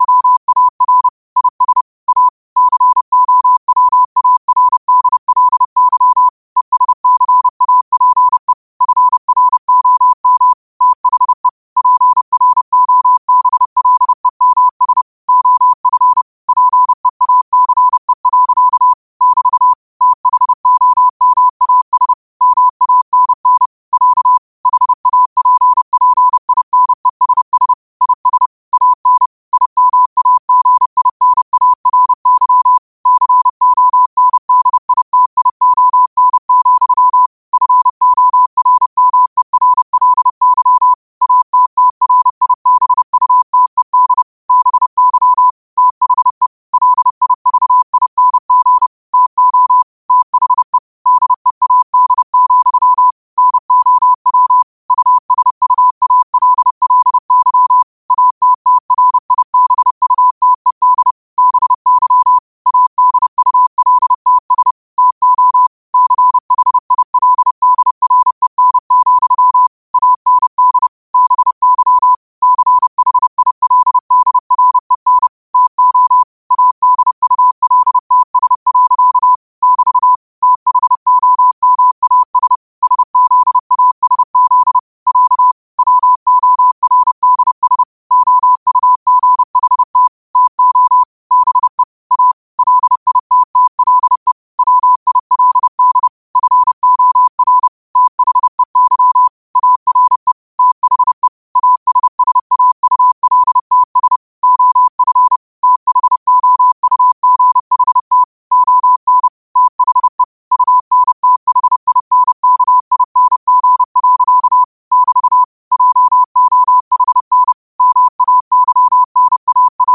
30 WPM morse code quotes for Sun, 17 Aug 2025 by QOTD at 30 WPM
Quotes for Sun, 17 Aug 2025 in Morse Code at 30 words per minute.